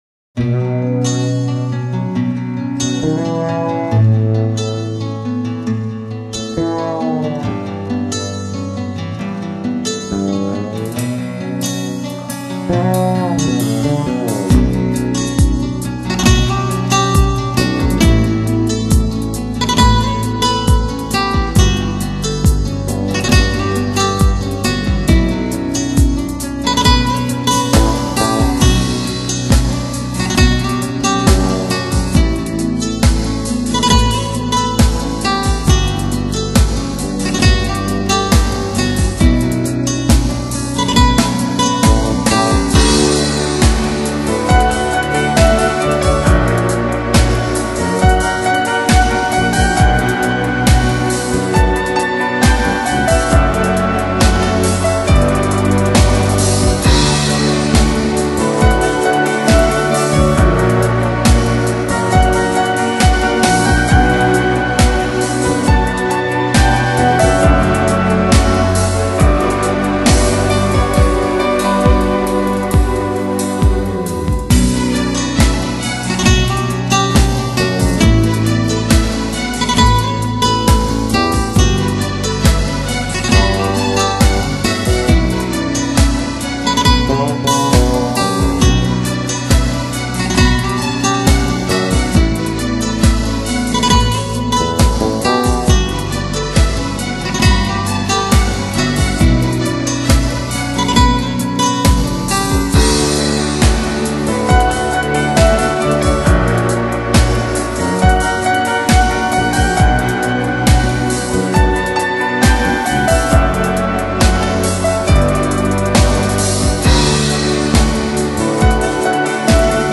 大部分的乐器演奏加上一些人声穿插其间，让人心情活跃而松弛，组成了一个让人心动的神秘暗示。